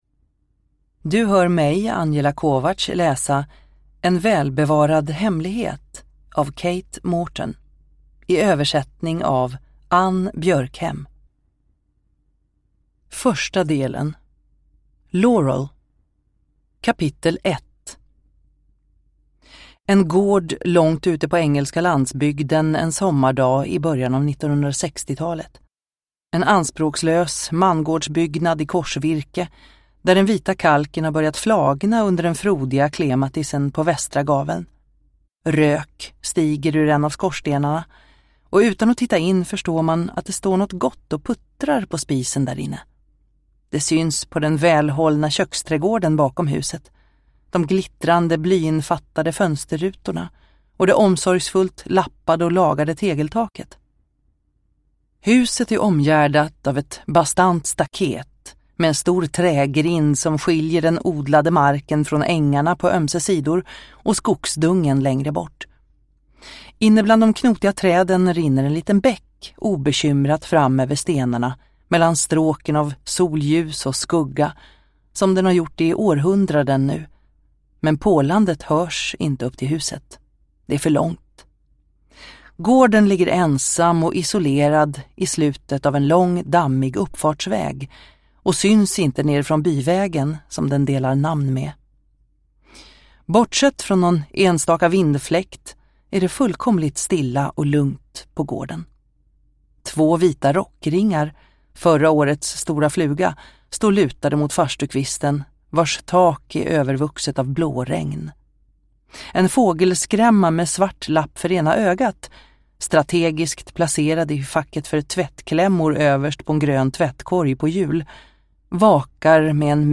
En välbevarad hemlighet – Ljudbok – Laddas ner